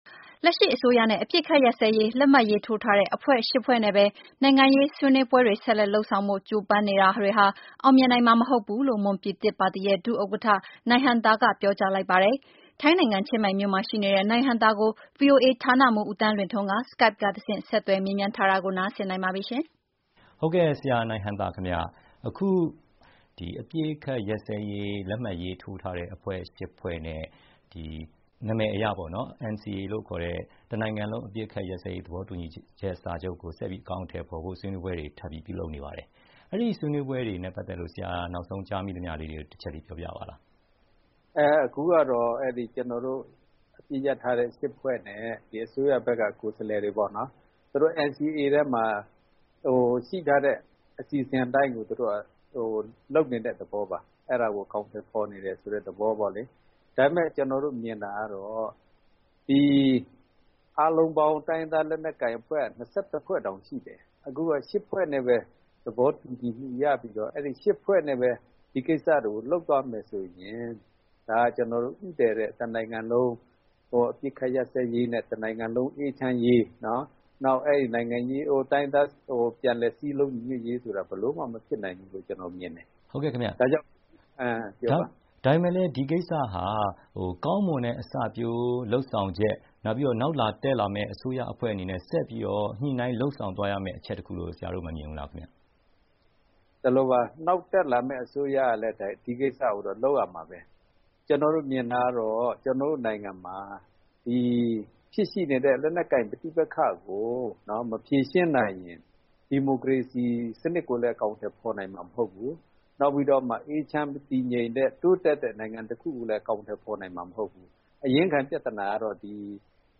ဗွီအိုအေ မေးမြန်းချက်